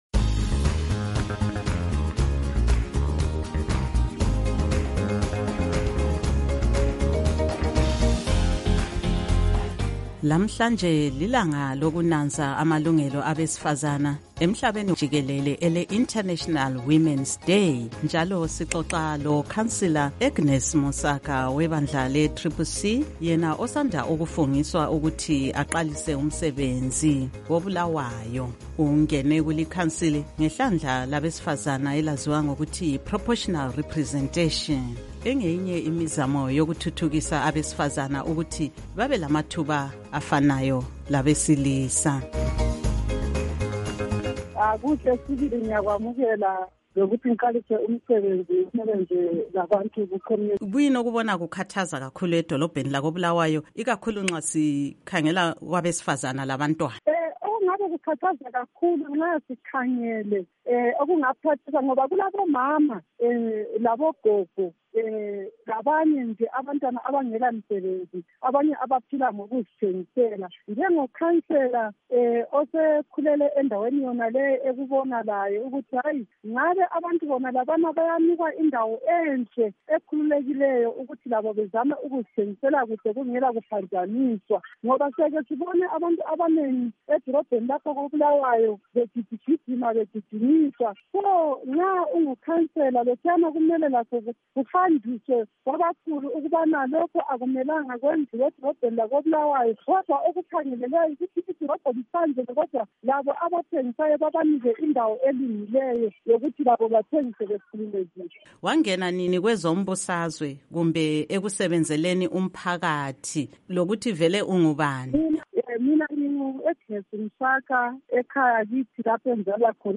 Ingxoxo loNkosazana Agnes Munsaka